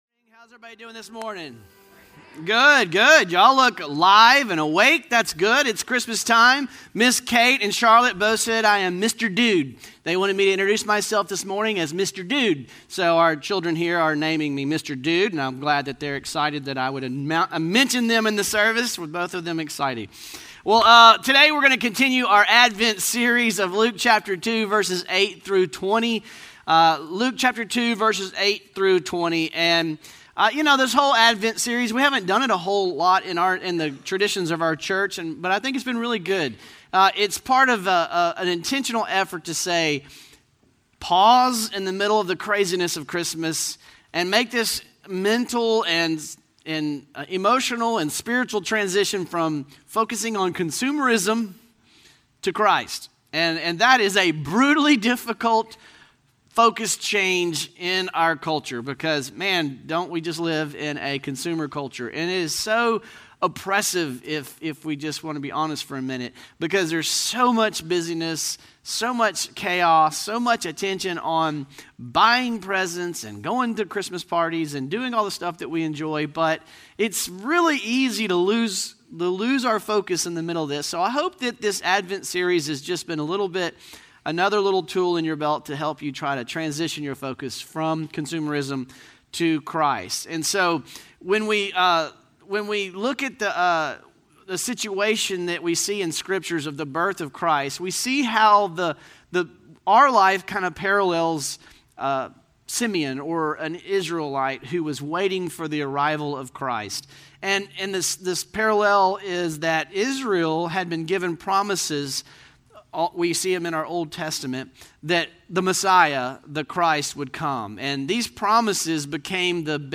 Norris Ferry Sermons Dec. 11, 2022 -- Christmas Heralds -- Luke 2:8-20 Dec 11 2022 | 00:40:11 1x 00:00 / 00:40:11 Subscribe Share Spotify RSS Feed Share Link Embed